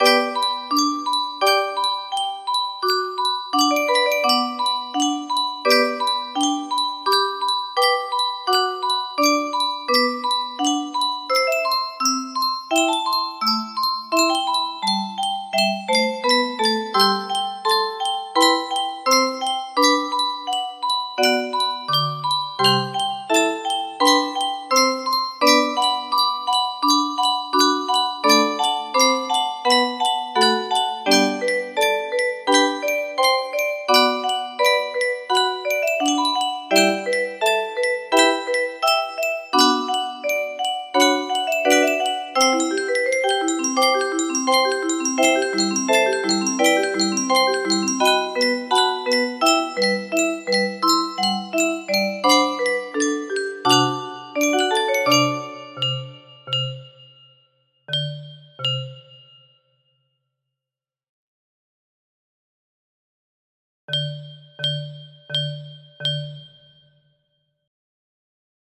SCISHORT music box melody